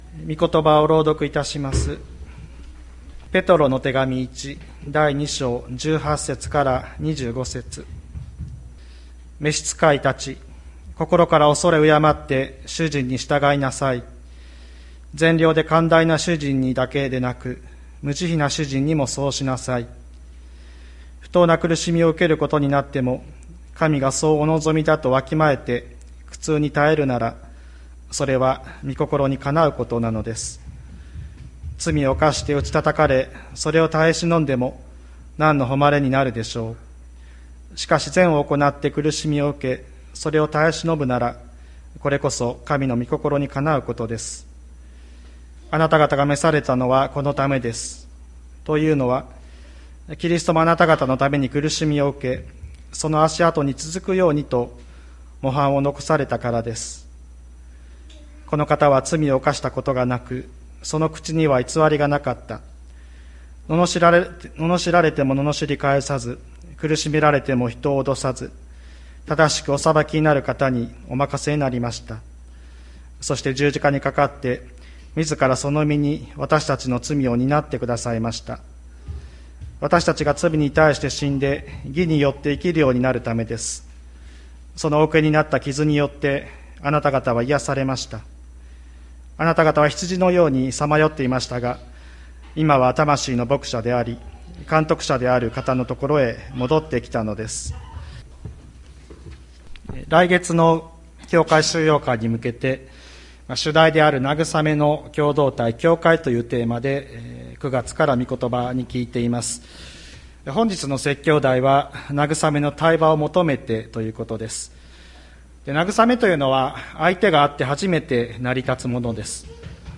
千里山教会 2022年09月18日の礼拝メッセージ。来月の教会修養会に向けて、主題である「慰めの共同体・教会」というテーマで９月から御言葉に共に聞いています。本日の説教題は「慰めの対話を求めて」ということです。